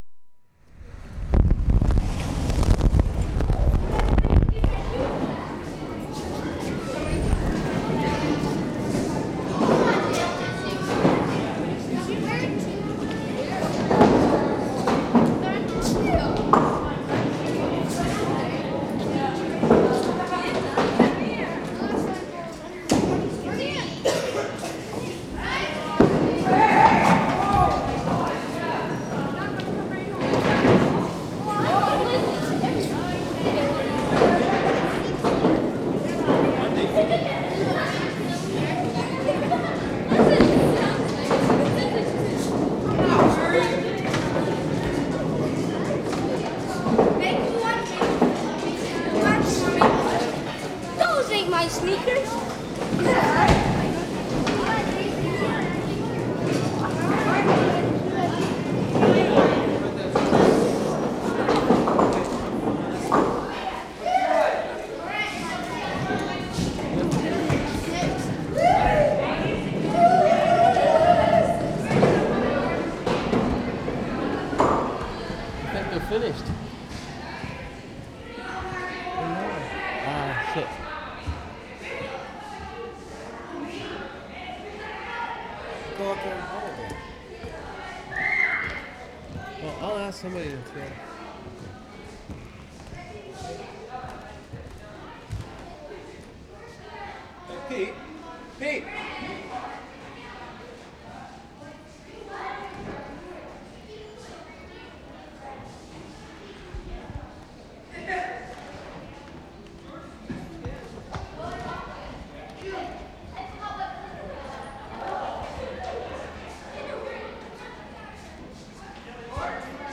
BOWLING ALLEY, CAMPBELL RIVER 3'15"
2. Bad wind as recordists enter. Lots of kids' voices, game quickly over.